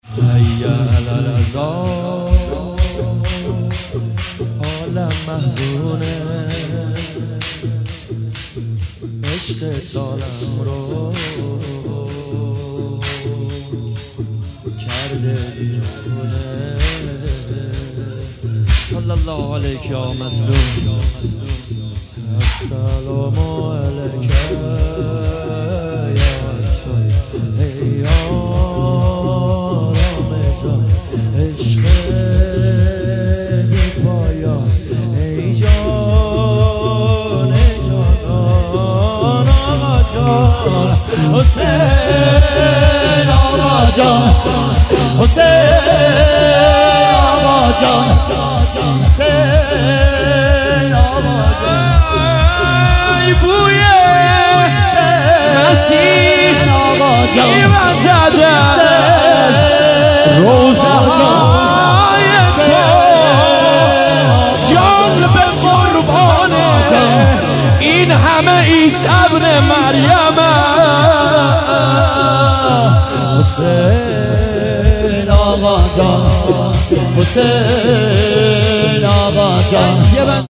تک نوحه